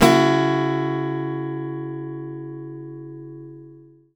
OVATION FIS7.wav